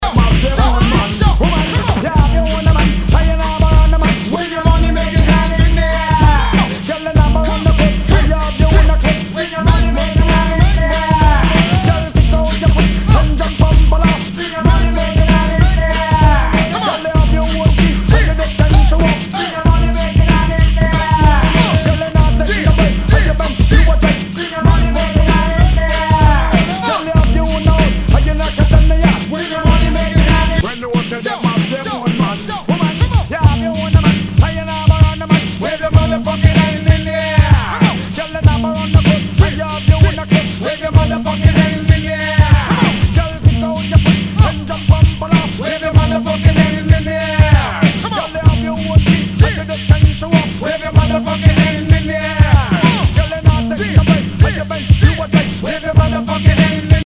HIPHOP/R&B
ナイス！ラガ・ヒップホップ！
ジャケにスレキズ、ヨゴレ、角潰れ、破れ、抜けあり。盤に傷あり、全体に大きくチリノイズが入ります